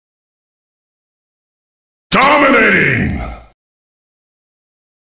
Play, download and share cs-dominating original sound button!!!!
dominating-cs-sound-.mp3